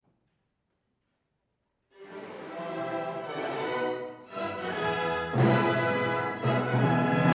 The triads as well as the semiquavers for the strings can be recognised easily.
In bar 5, the character slightly changes.
In terms of instrumentation Strauss chose, as it was usual for the Romantic period, a big orchestra, the instruments were the following: 3 flutes, English horn, 2 clarinets, 2 bassoons, contrabassoon, 4 horns, 3 trumpets, 3 trombones tuba, harp, timpani, triangle, cymbal, chime and strings. 10